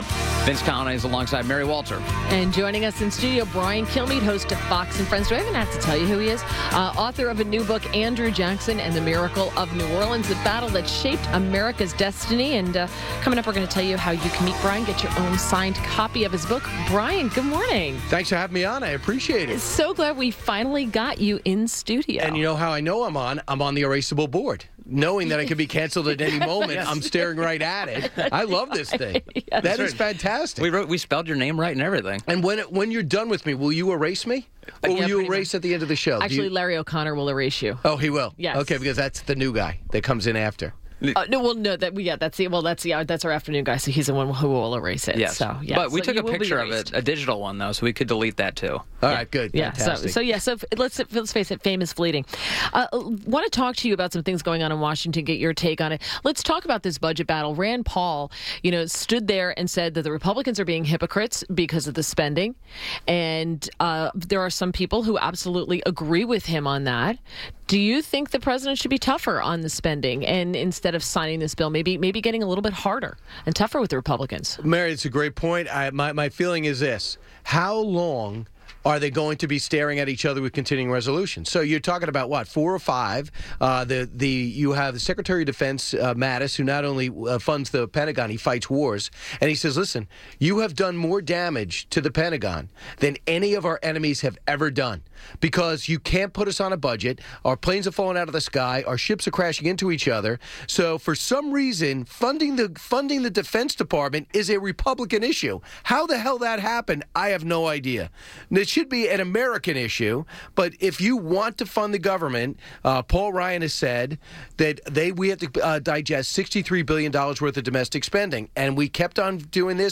WMAL Interview - BRIAN KILMEADE - 02.09.18